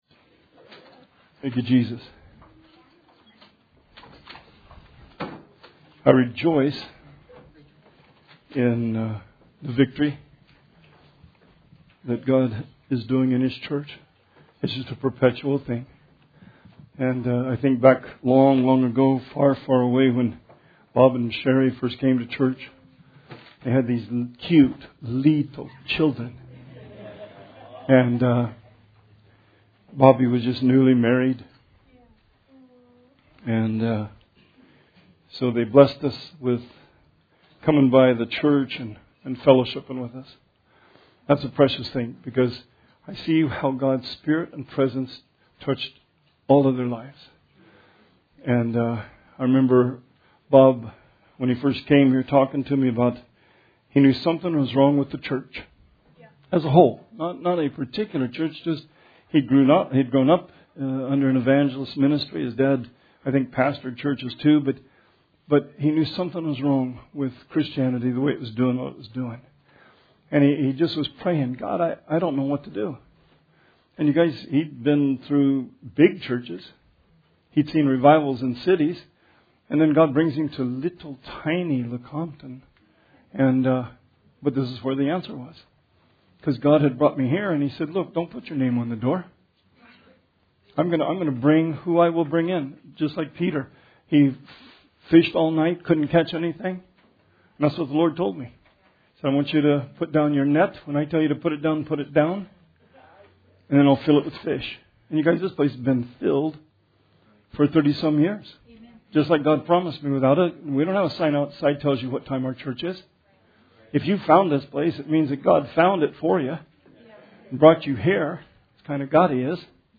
Sermon 7/19/20